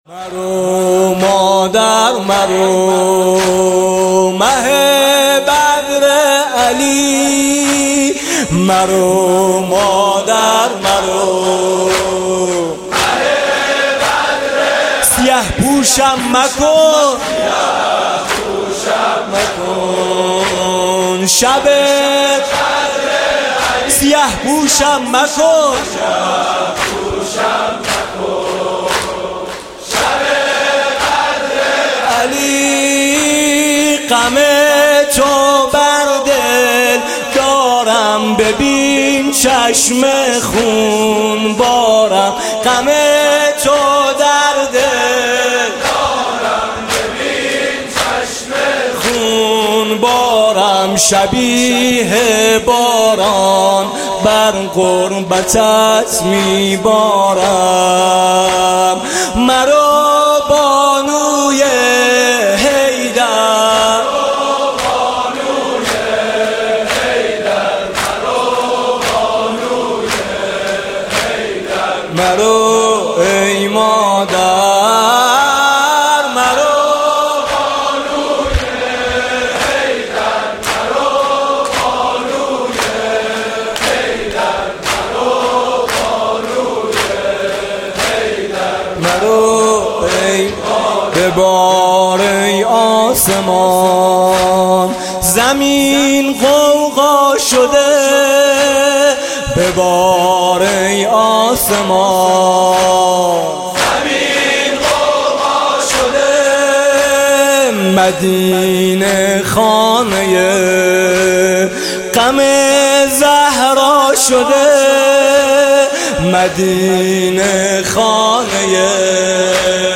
مداحی فاطمیه 93 با نوای "حاج میثم مطیعی" + دانلود | انجمن گفتگوی دینی
:Sham:شب سوم فاطمیه اول 93 هیئت میثاق با شهدا ::Sham: